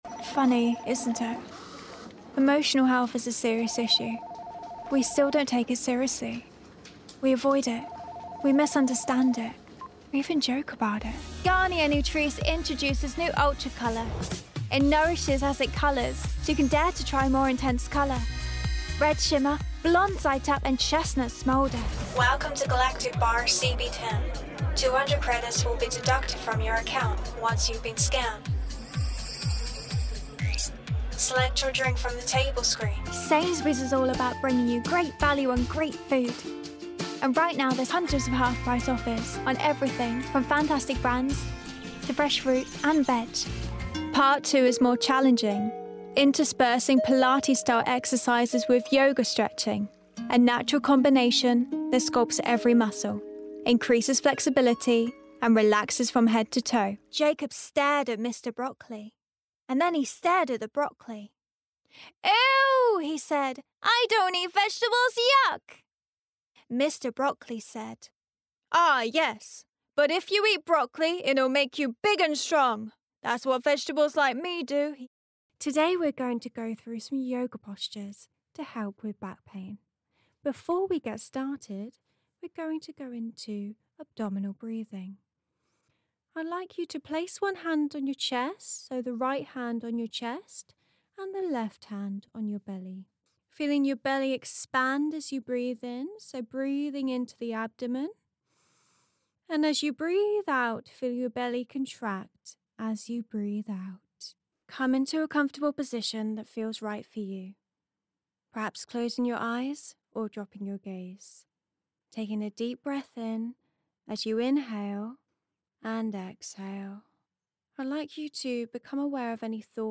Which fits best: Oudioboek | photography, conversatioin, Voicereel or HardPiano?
Voicereel